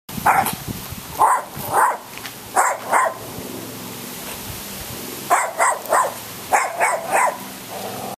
Small Dog Barking Sound Effect Download: Instant Soundboard Button
Dog Barking Sound487 views